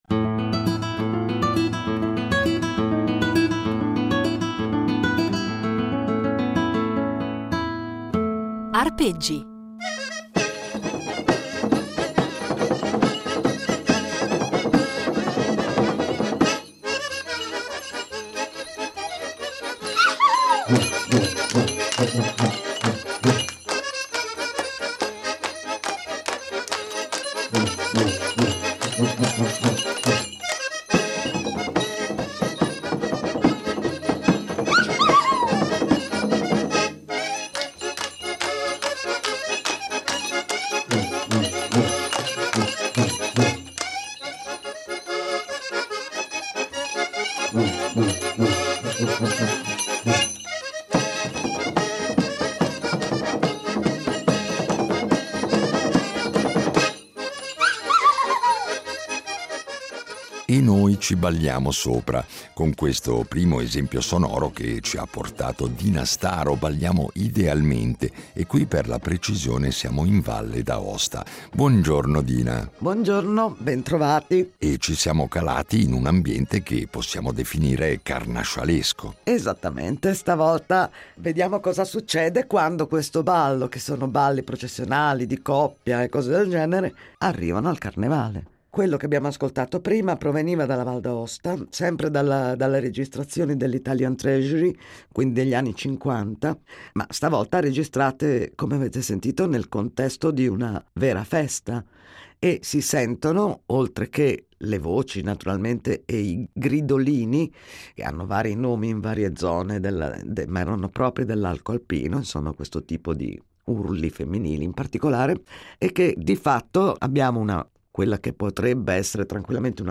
Un itinerario sonoro ricco di materiale inedito, registrato sul campo e negli anni da lei stessa